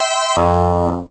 alert_3.ogg